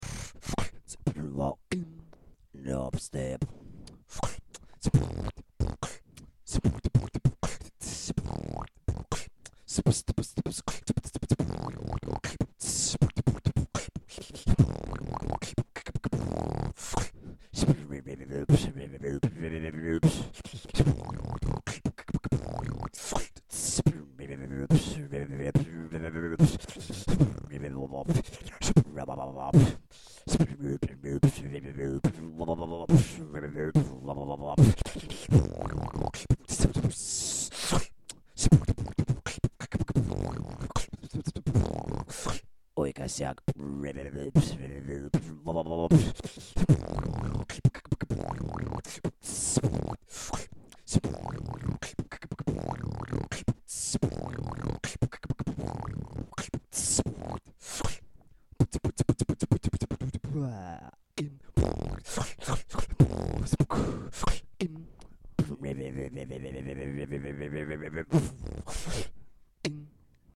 Форум российского битбокс портала » Реорганизация форума - РЕСТАВРАЦИЯ » Выкладываем видео / аудио с битбоксом » Dupstep
Dupstep
Давно не битовал:D....Ну и косяки маленькие:D